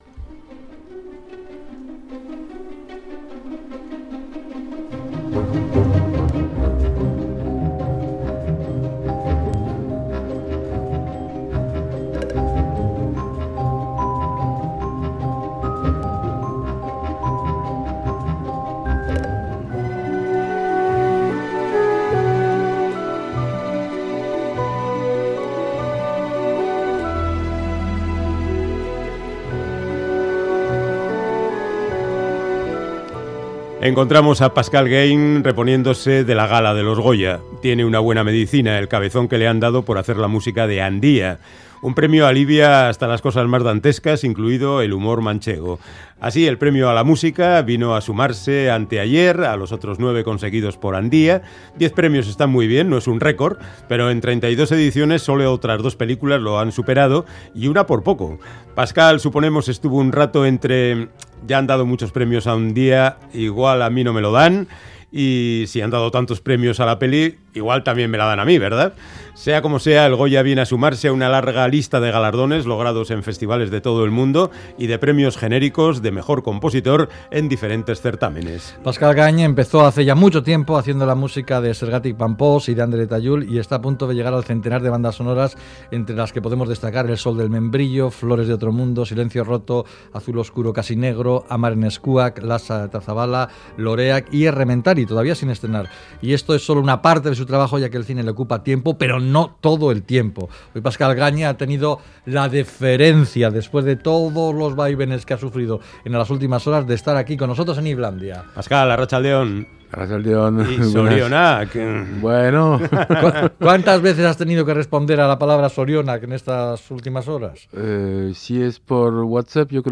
Audio: Charlamos con el gran compositor vasco Pascal Gaigne, pocas horas después de ganar el Goya a la mejor banda sonora por Handia, que conquistó diez galardones en los premios del cine español